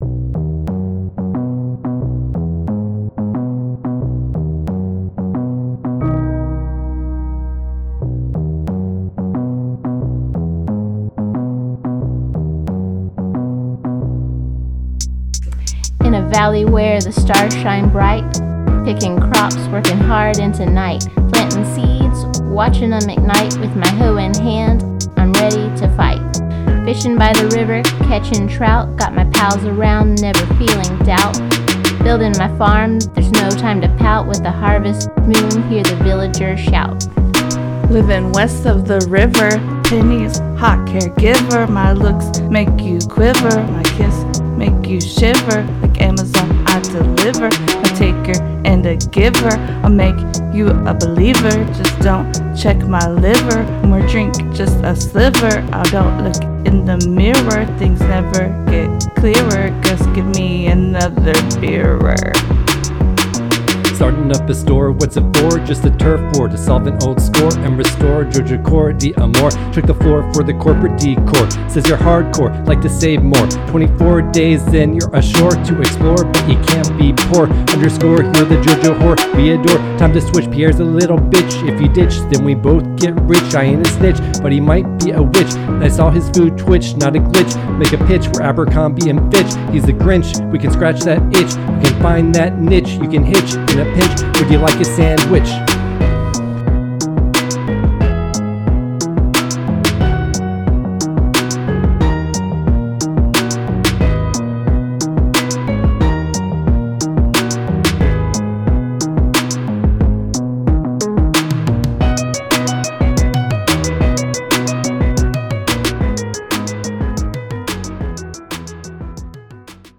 Rap from Episode 85: Stardew Valley – Press any Button
Stardew-Valley-Rap.mp3